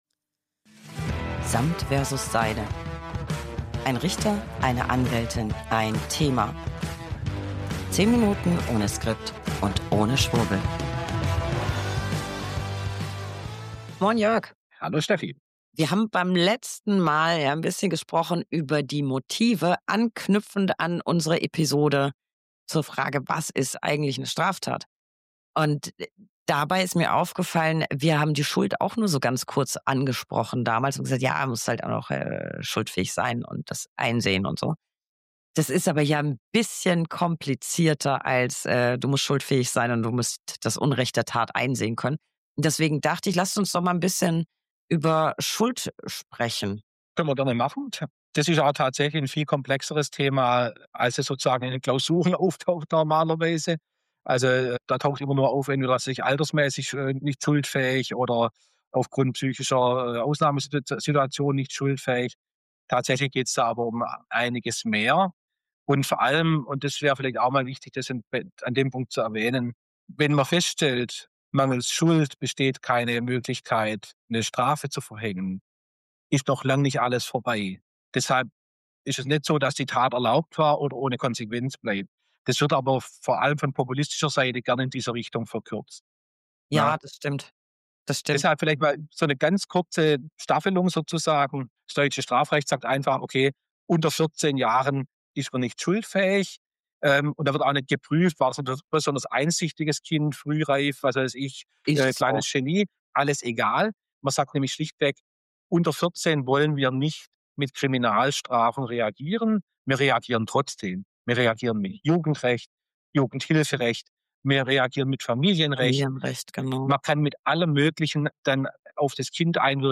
Beschreibung vor 4 Monaten 1 Anwältin + 1 Richter + 1 Thema. 10 Minuten ohne Skript und ohne Schwurbel. Rechtswidrige Tat begangen und trotzdem nicht schuldig?